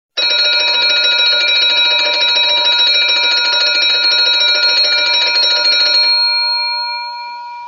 antique-alarm_24834.mp3